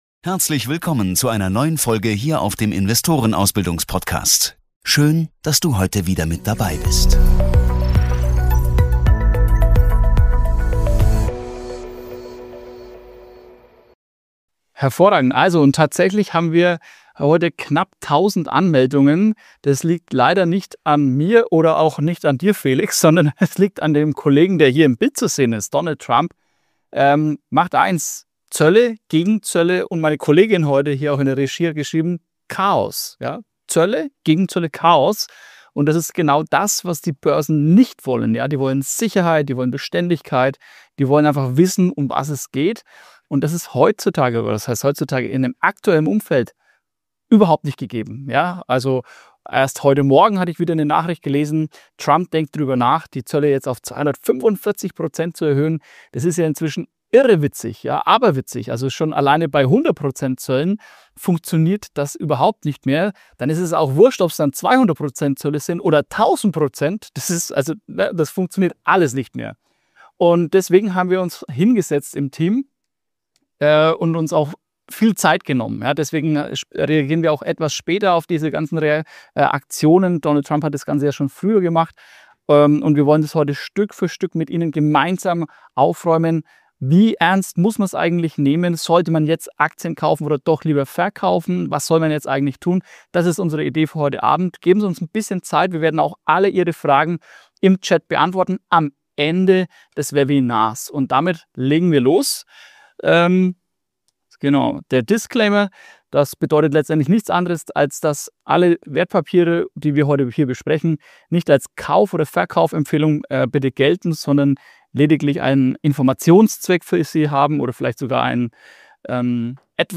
Beschreibung vor 11 Monaten Panik an den Börsen wegen Trump's Zollpolitik? Erfahre in diesem Webinar, wie ernst die Lage wirklich ist und ob jetzt Kauf- oder Verkaufszeit ist.